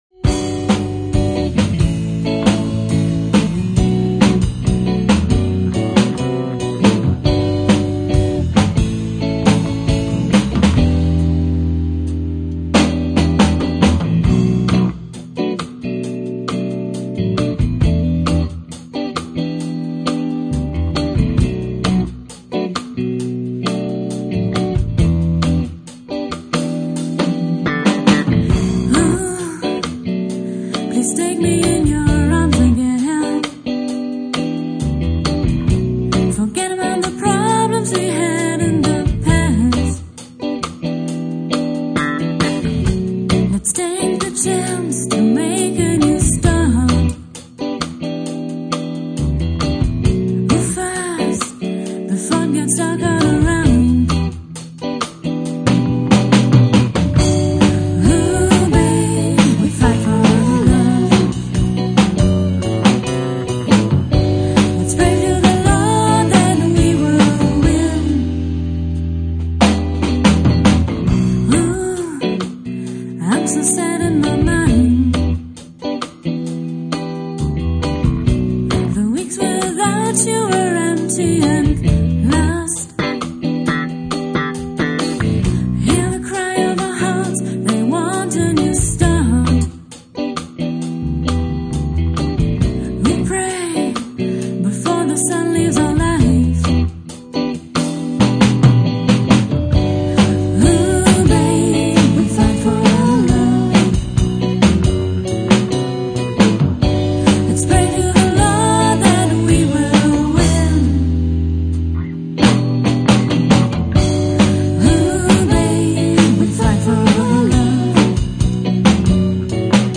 Gesang
Gitarre
Schlagzeug, Percussion